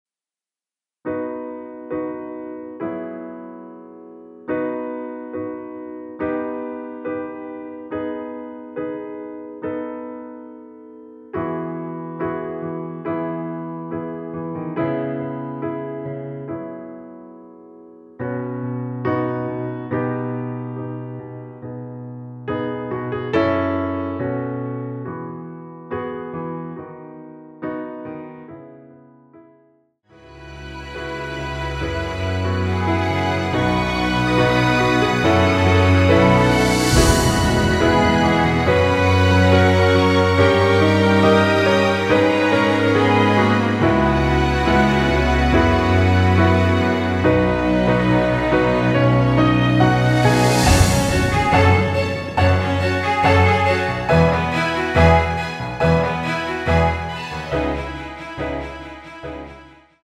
전주 없는 곡이라 전주 1마디 만들어 놓았습니다.(미리듣기 참조)
앞부분30초, 뒷부분30초씩 편집해서 올려 드리고 있습니다.
중간에 음이 끈어지고 다시 나오는 이유는